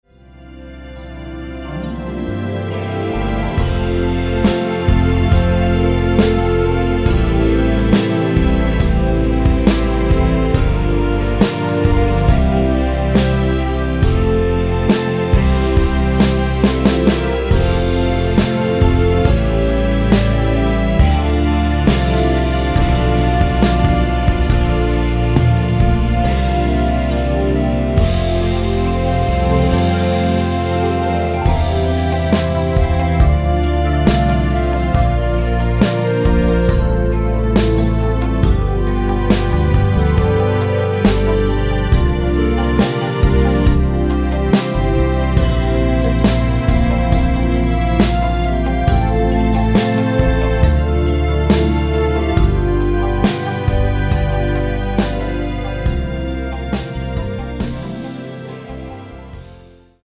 원키에서(-2)내린 (짧은편곡) 멜로디 포함된 MR입니다.
노래방에서 노래를 부르실때 노래 부분에 가이드 멜로디가 따라 나와서
앞부분30초, 뒷부분30초씩 편집해서 올려 드리고 있습니다.
중간에 음이 끈어지고 다시 나오는 이유는